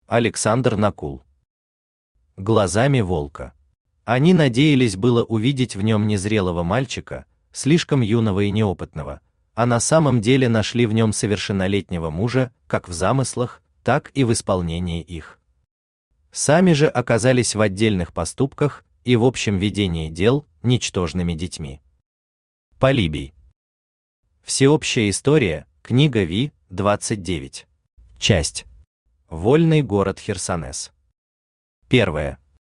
Аудиокнига Глазами волка | Библиотека аудиокниг
Aудиокнига Глазами волка Автор Александр Накул Читает аудиокнигу Авточтец ЛитРес.